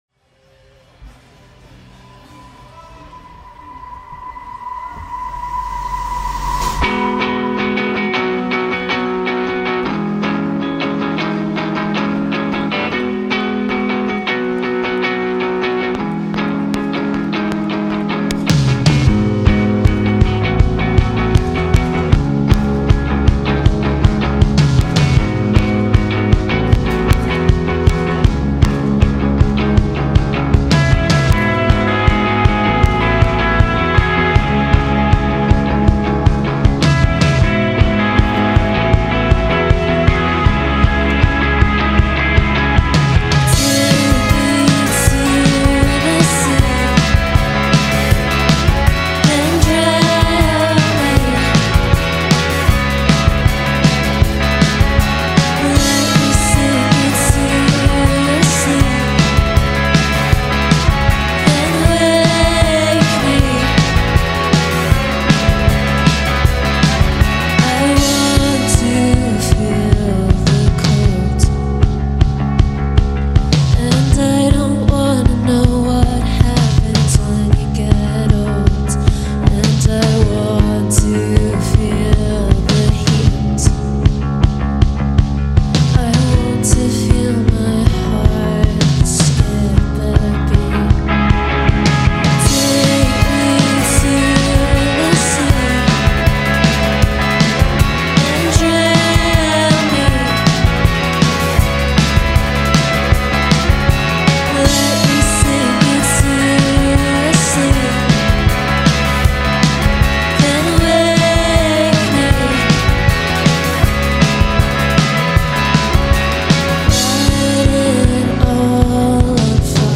Proudly part of what is rapidly becoming a Shoegaze revival